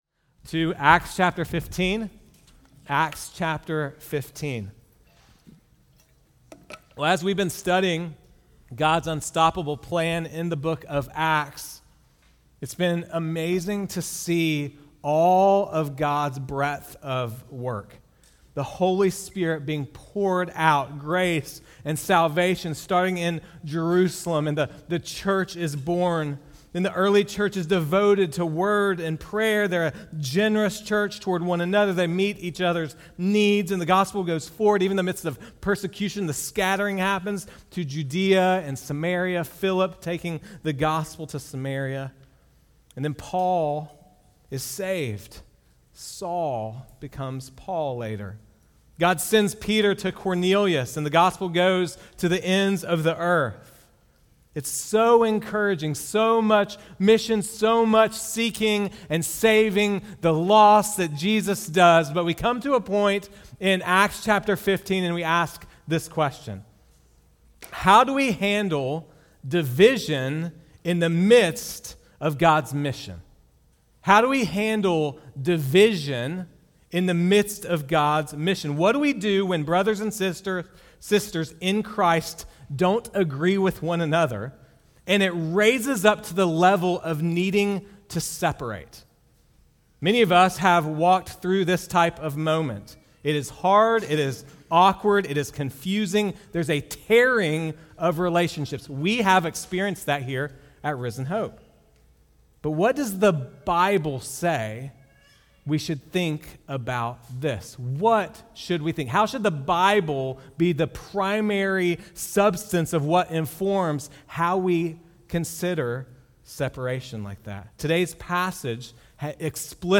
Sermons | Risen Hope Church